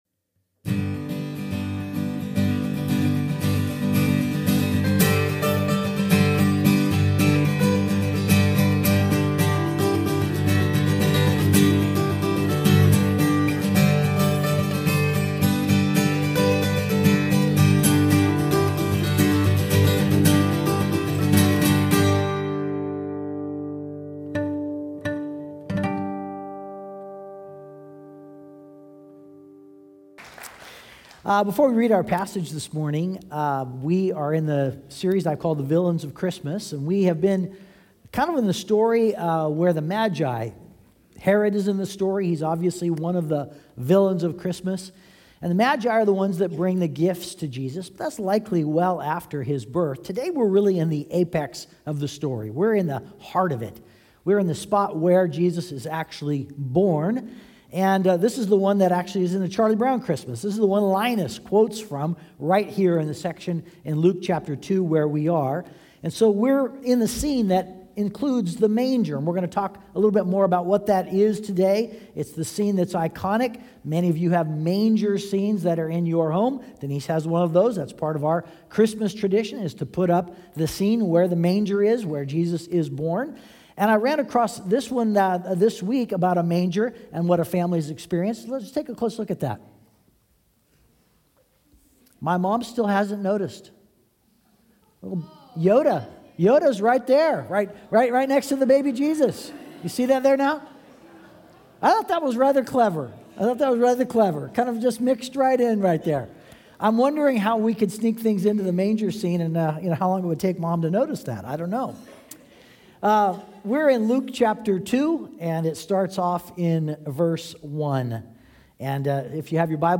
Sermon Podcast from Community Christian Fellowship in Edmonds, WA.